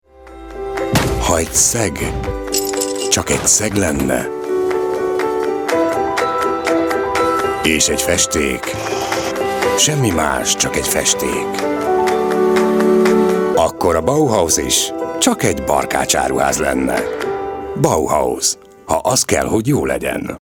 He sido un locutor húngaro profesional a tiempo completo durante más de 20 años.
Cálido
Sexy
Conversacional